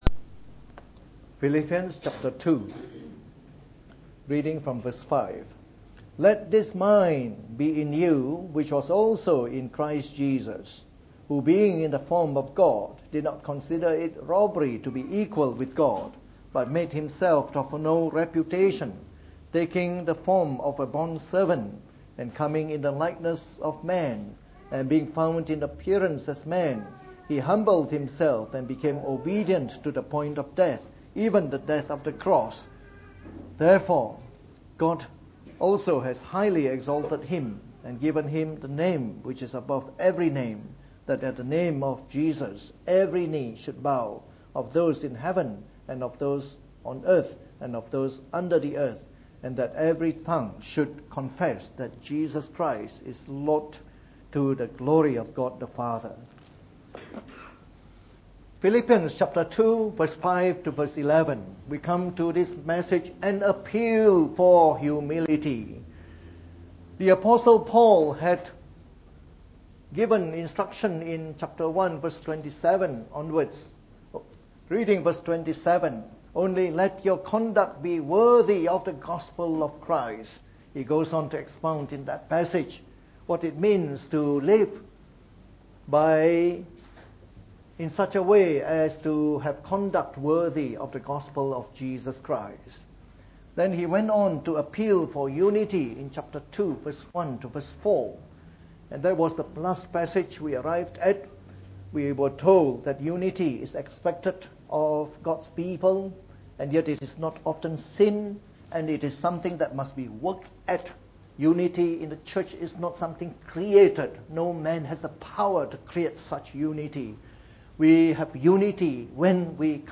From our series on the Epistle to the Philippians delivered in the Morning Service.